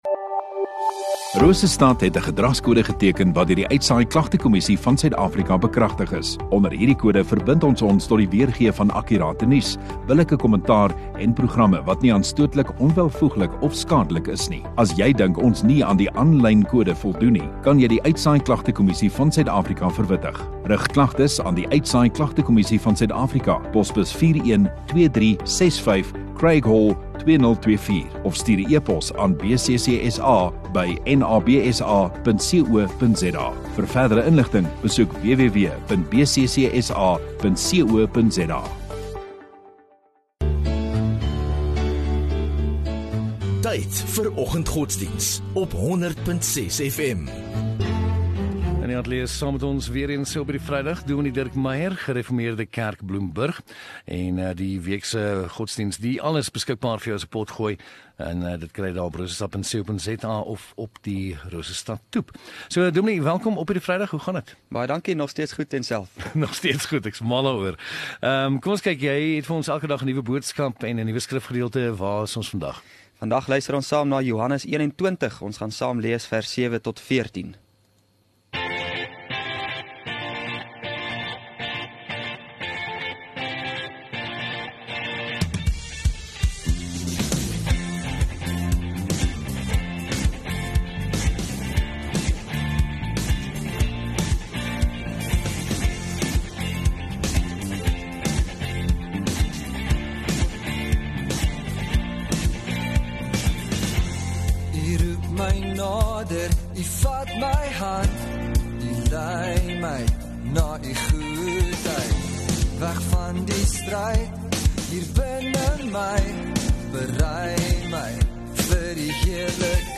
3 May Vrydag Oggenddiens